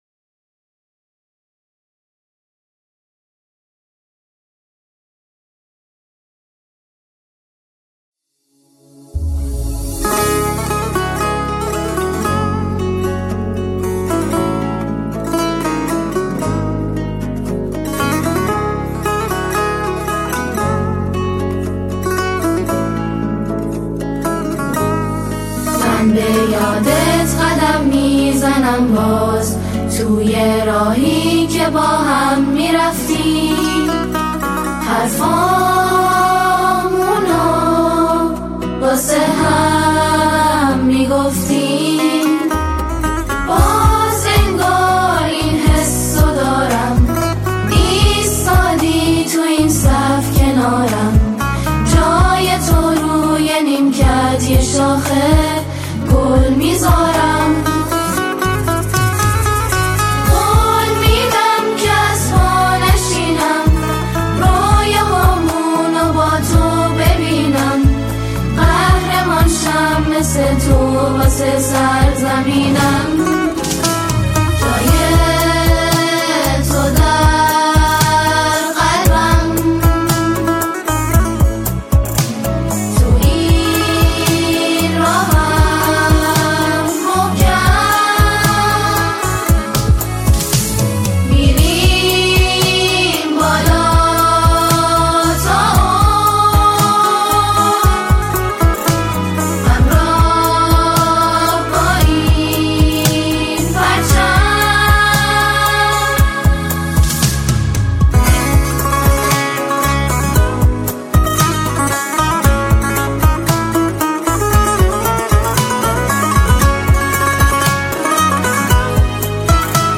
اثری احساسی، فاخر و هویت‌ساز
ژانر: سرود